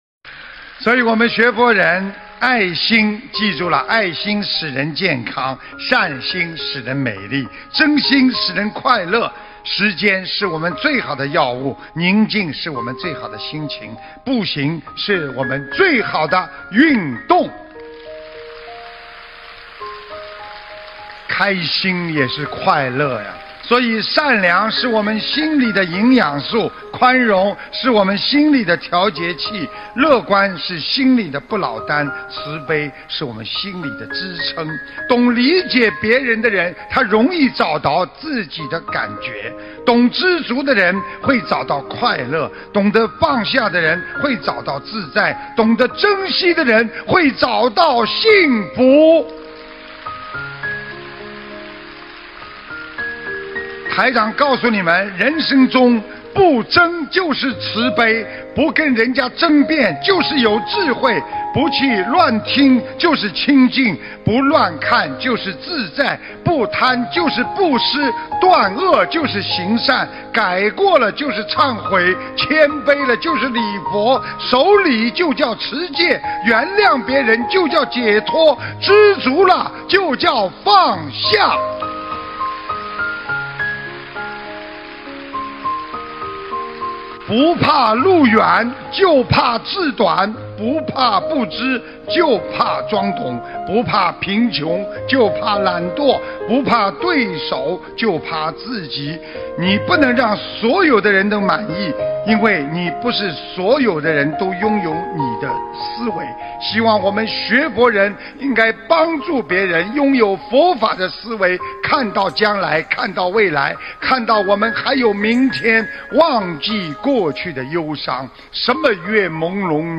音频：不争就是慈悲！2019年1月27日！悉尼法会